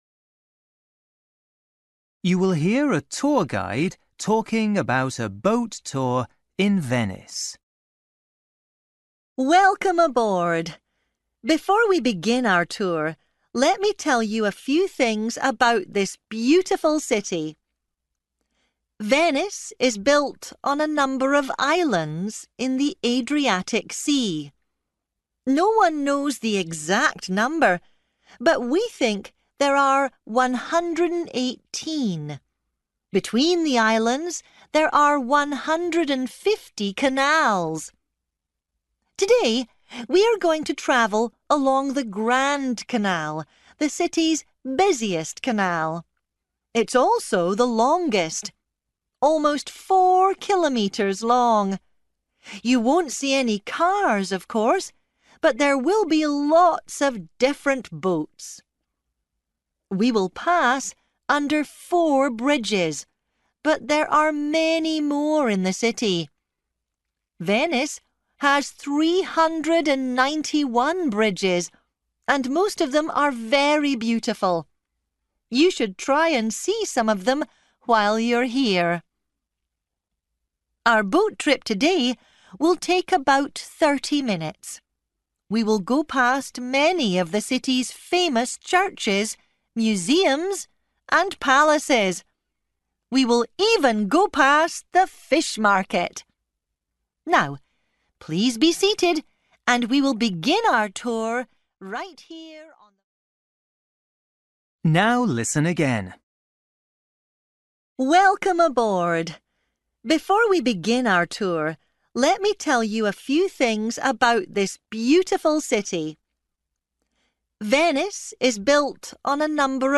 You will hear a tour guide talking about a boat tour in Venice.